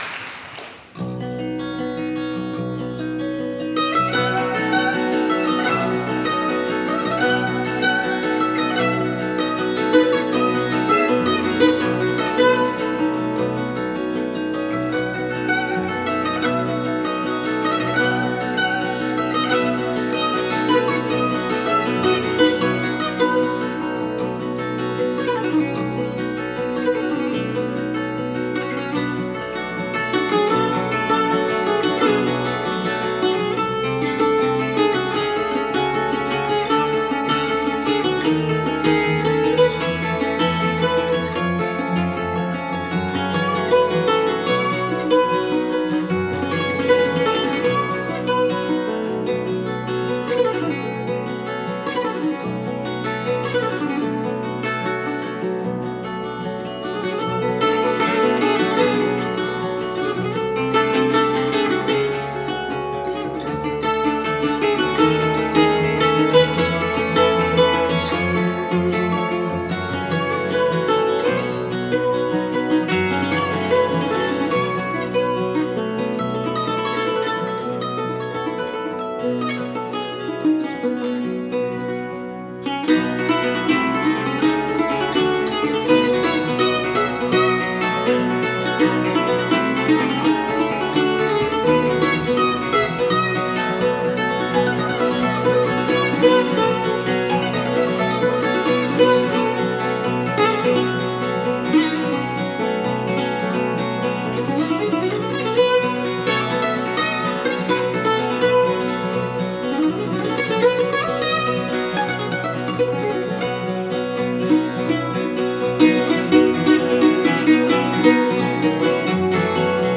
11 - 葡萄牙 就像图中所展示的， 葡萄牙吉他是一种梨形的、由六组双弦构成的吉他乐器。